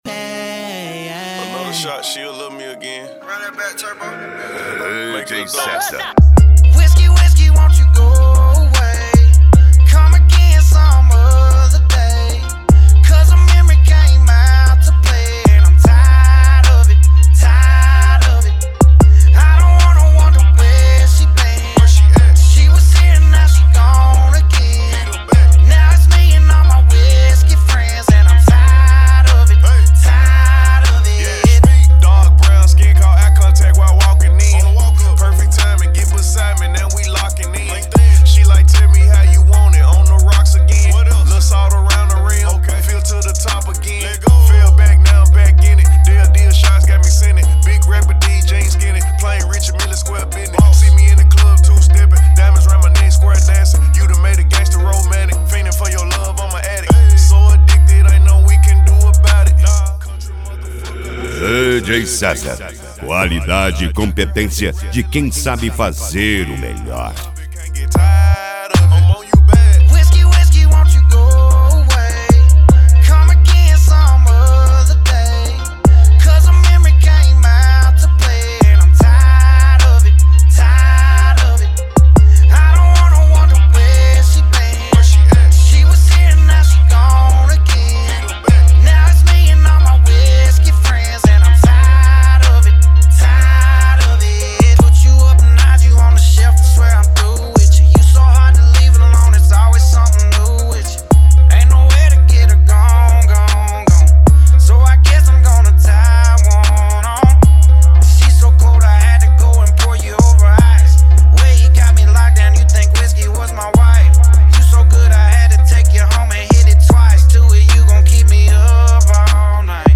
Funk
Mega Funk
Melody
Modao